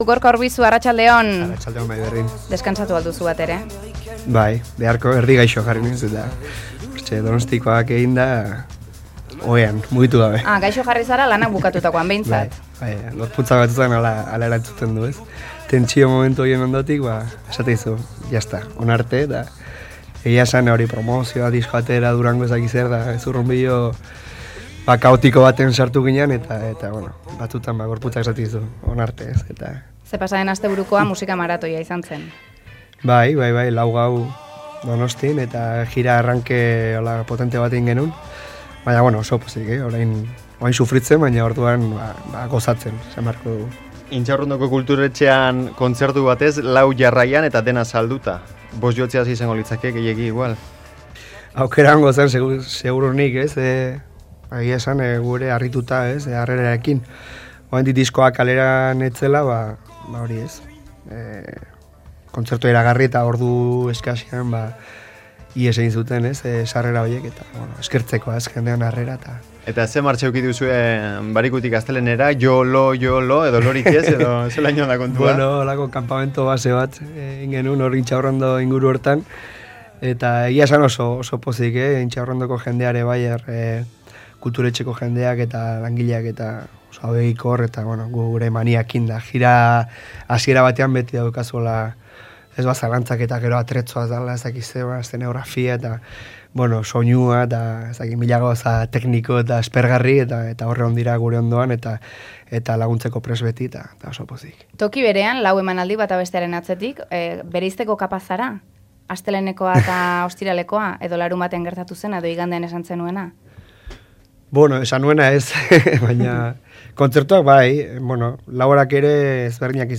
Akustikoa eskaini du Berri Txarrak taldeko abeslariak Hiri Gorrian